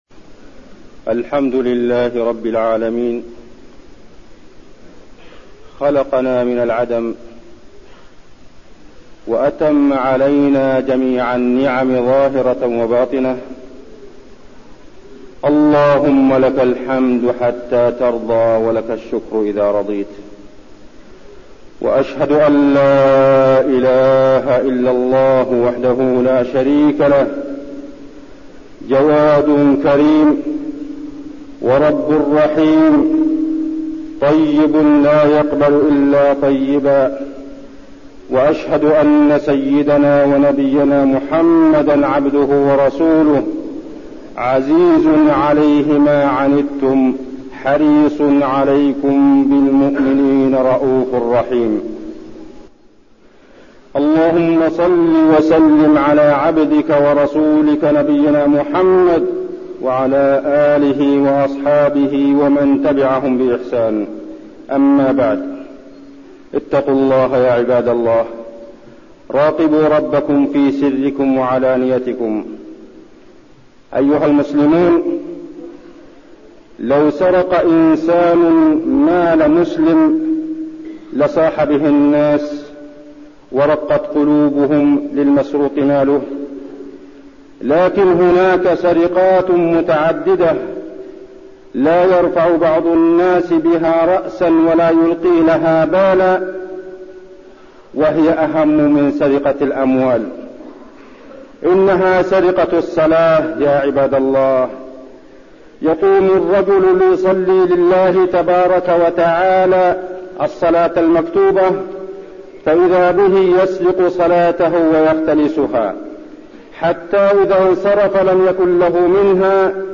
المكان: المسجد النبوي الشيخ: عبدالله بن محمد الزاحم عبدالله بن محمد الزاحم السرقة في الصلاة